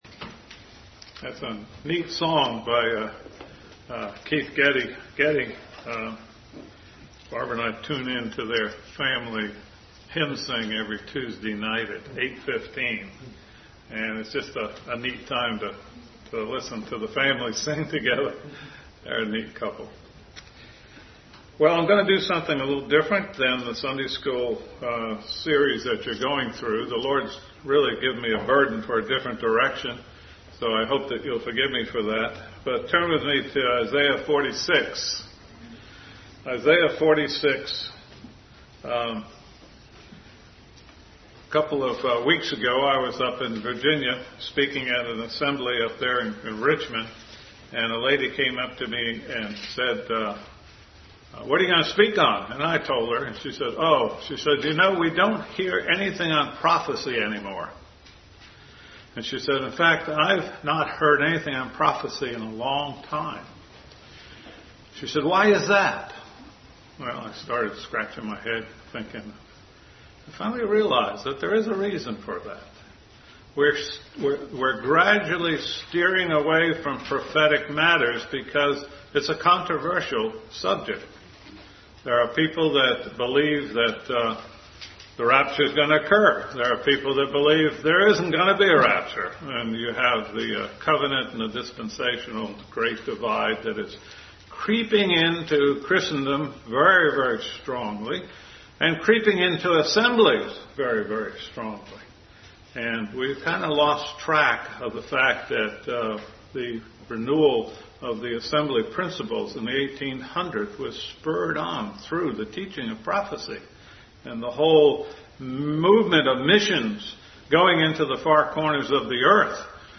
Bible Text: Isaiah 46:9-11, 48:3-6, John 14:1-4, 2 Thessalonians 2:1-3, 1 John 3:1-3. 1 Thessalonians 5:1-2, Zechariah 12:1-3, Revelation 17, Zechariah 5 | Adult Sunday School Class